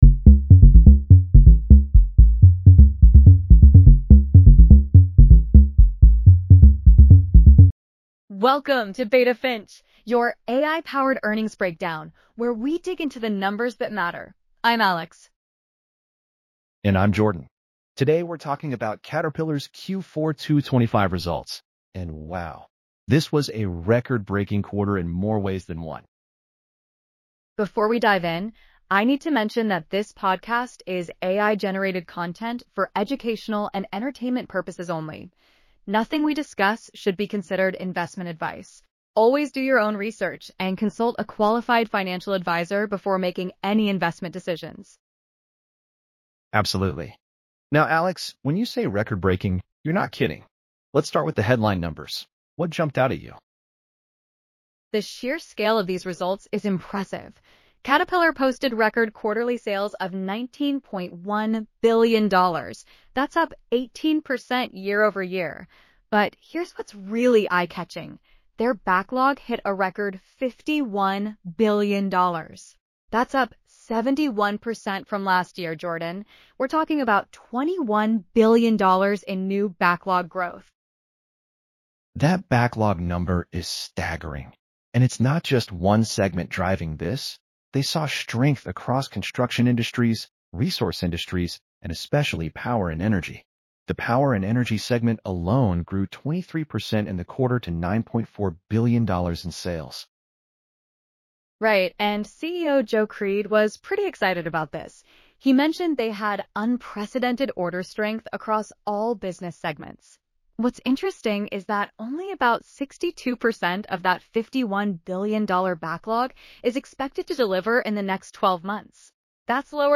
BETA FINCH PODCAST SCRIPT